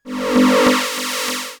Robot2.wav